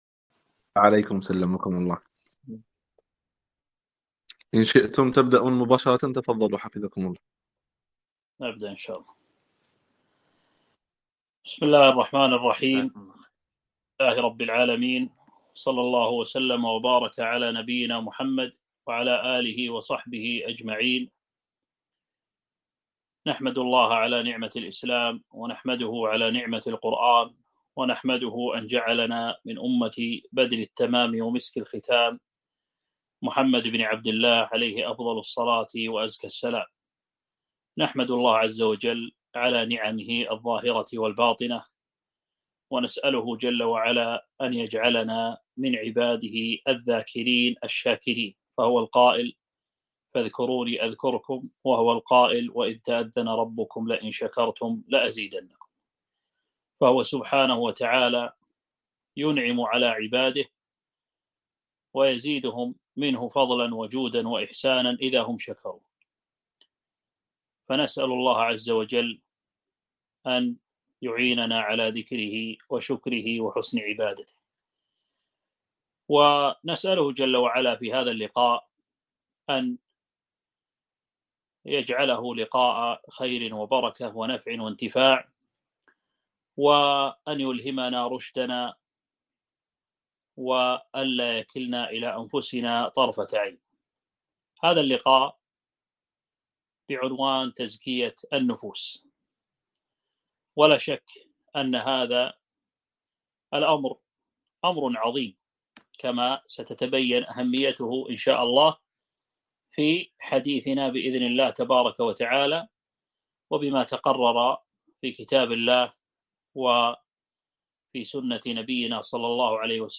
محاضرة - تزكية النفوس